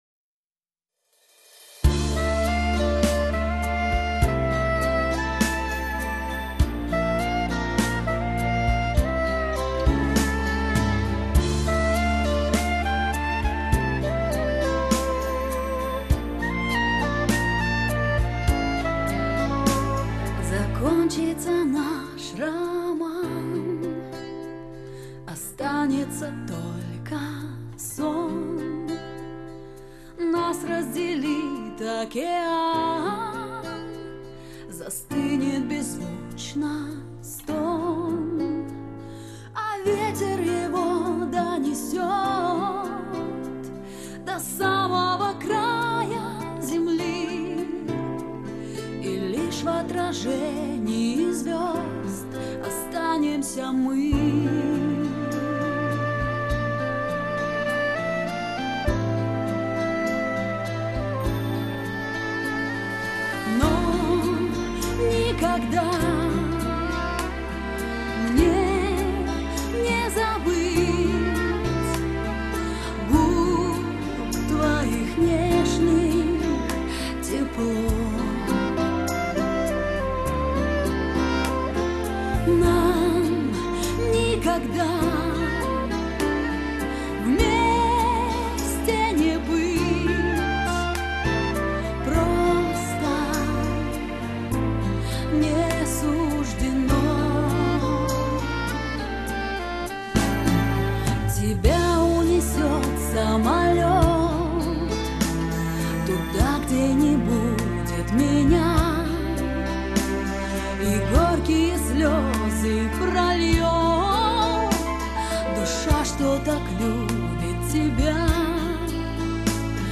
Лирическая, очень красивая для женского вокала
живые гитары, сопрано саксофон